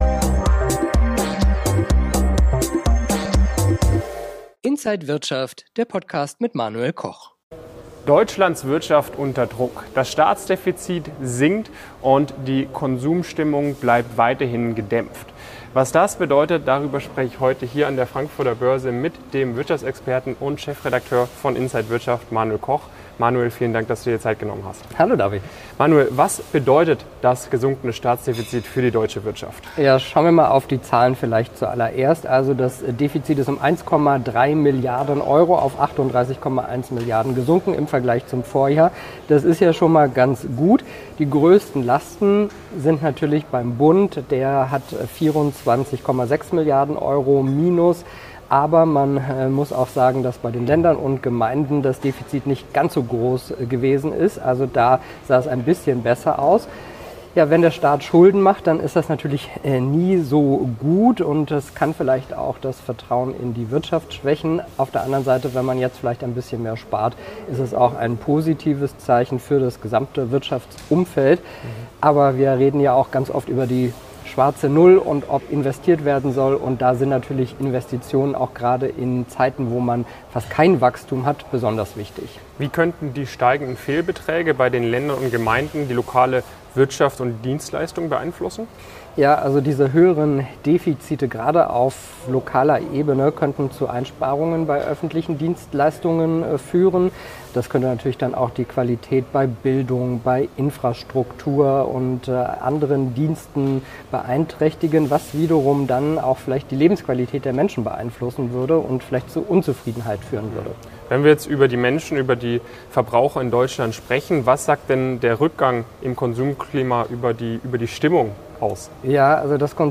Alle Details im Interview
an der Frankfurter Börse